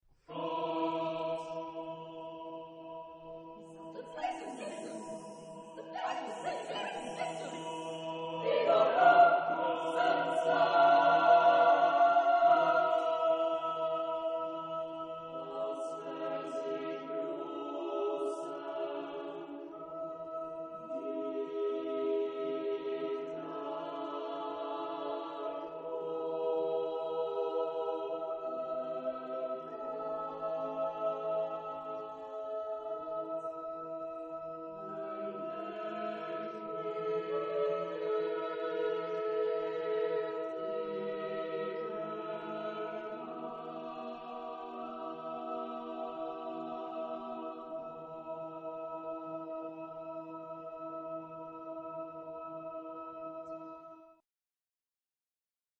Genre-Style-Form: Sacred ; Motet
Mood of the piece: dramatic
Type of Choir: SSATBB  (6 mixed voices )